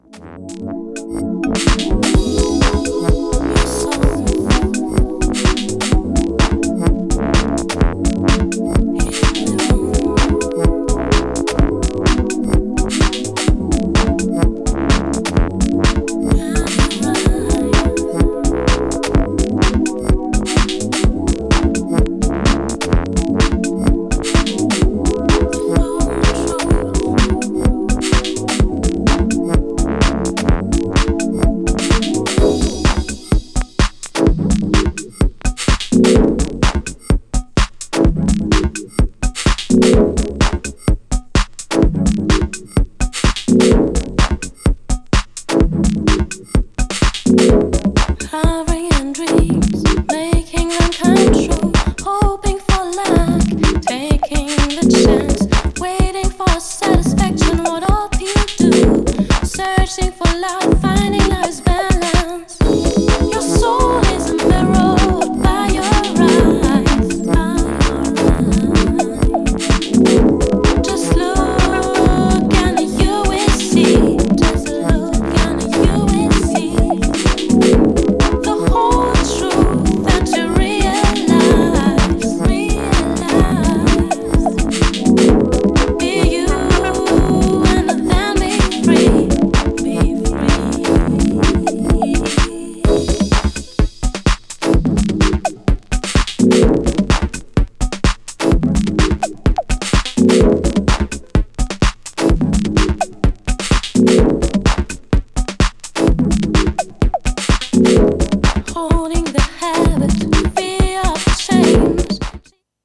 ミニマルなテックHOUSE TRACKに清楚な女性ボーカルが乗るDISCOテイストなサウンド!!
GENRE House
BPM 126〜130BPM